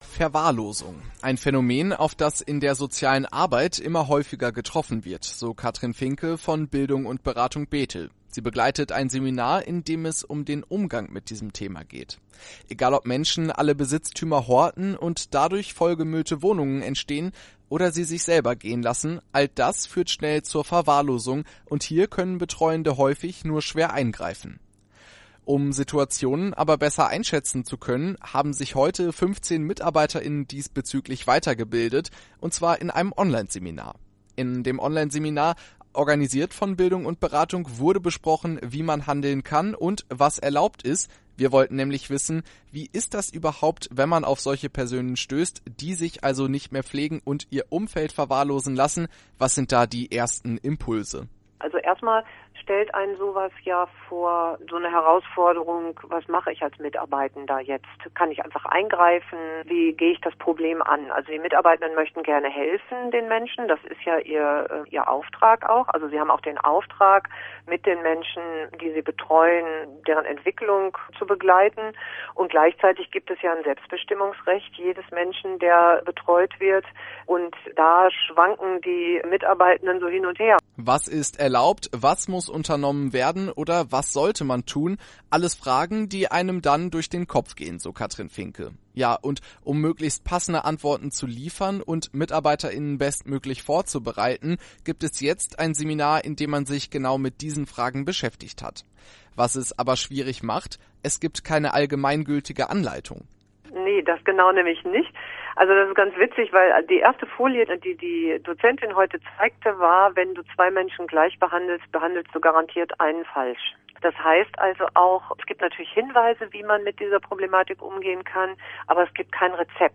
Beitrag über ein Seminar zu diesem Thema.